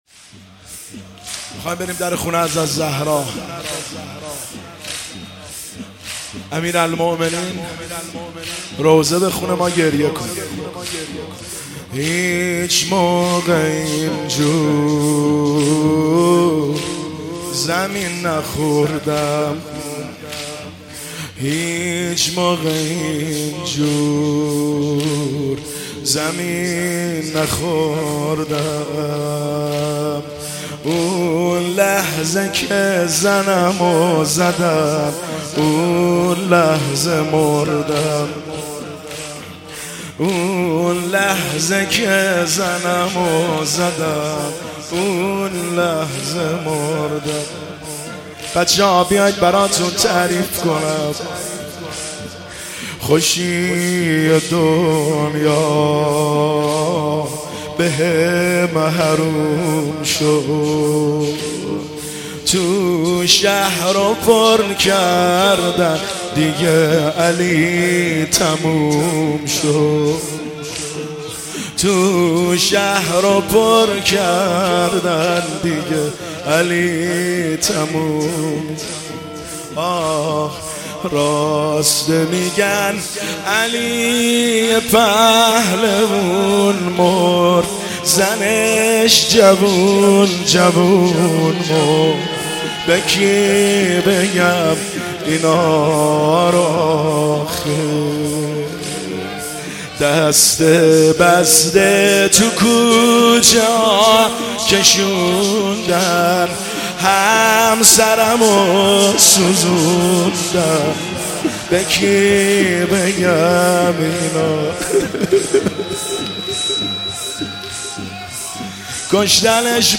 زمینه – شب 23 رمضان المبارک 1403